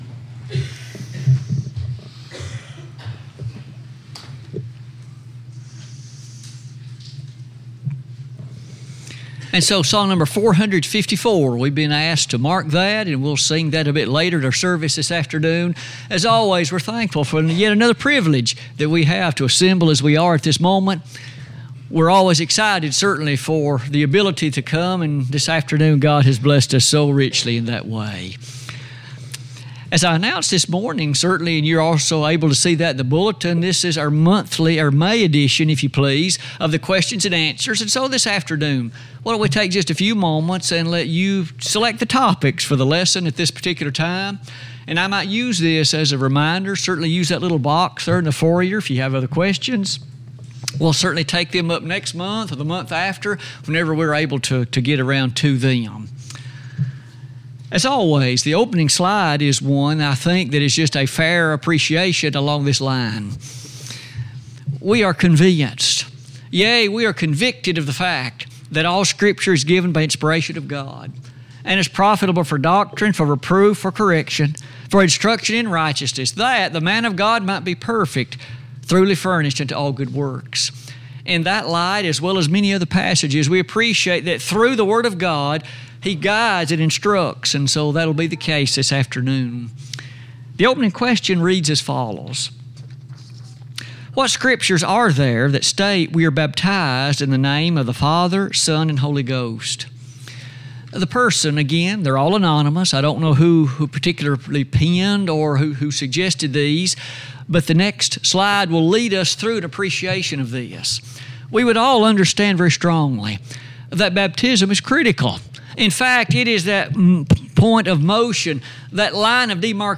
Sermons Recordings